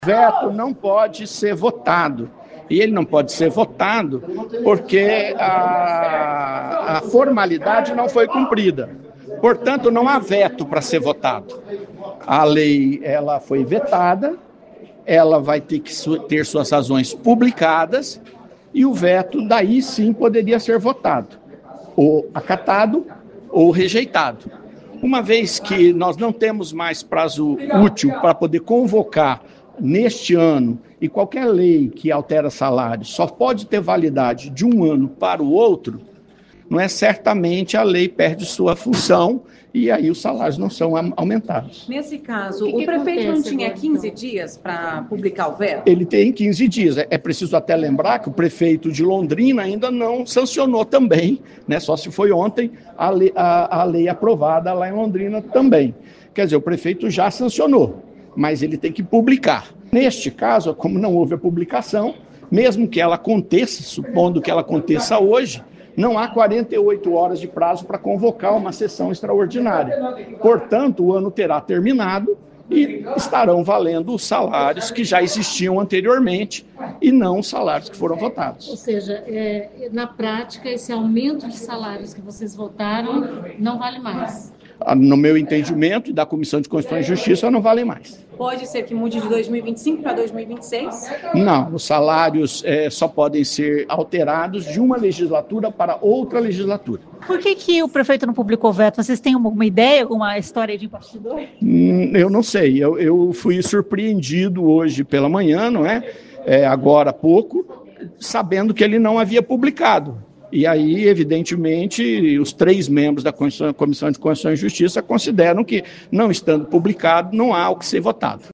O vereador Sidnei Telles, presidente da CCJ, explicou: